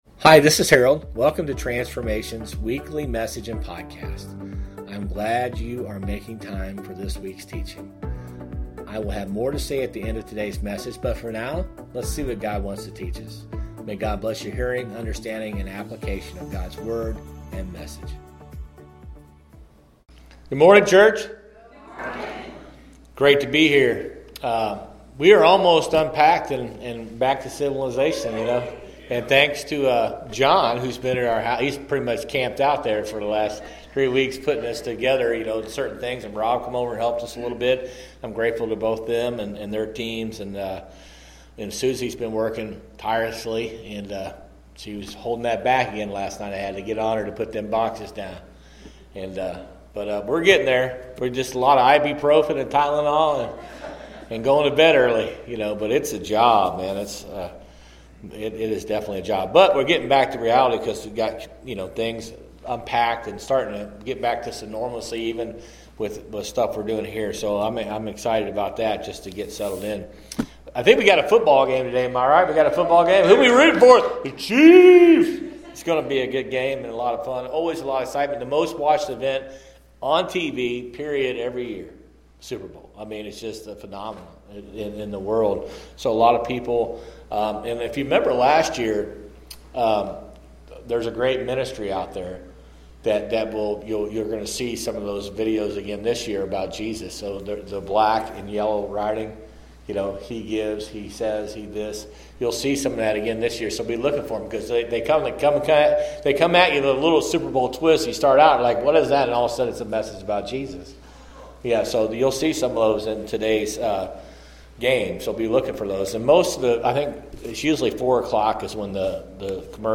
Sermons | Transformation Church